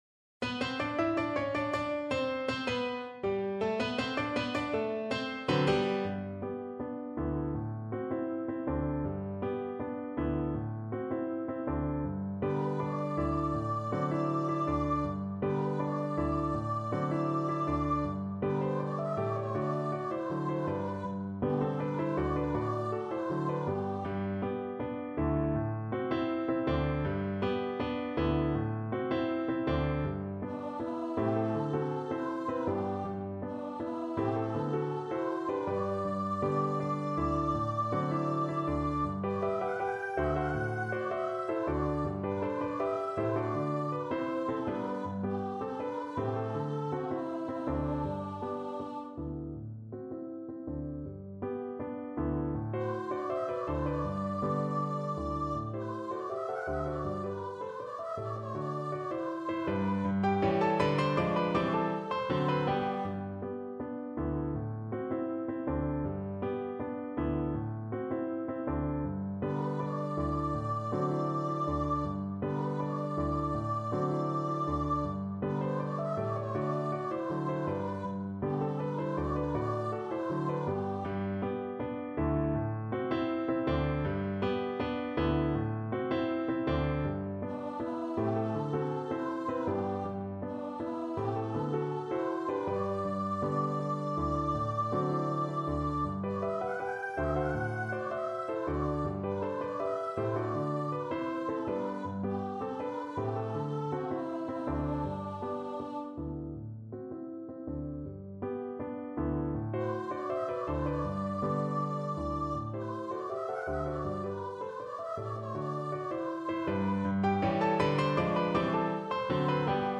Allegretto =80
2/2 (View more 2/2 Music)
D5-G6
Cuban